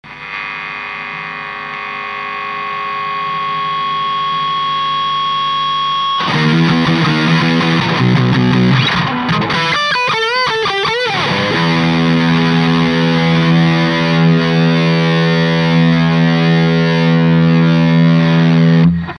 Guitar MoonStoratoShape
Amplifier VOX AD30VT UK'80S
全く違う音になりました。LM741CNと比較するとローが、かなり増してます。
ローだけ遅れてくる感じがしないでもありません。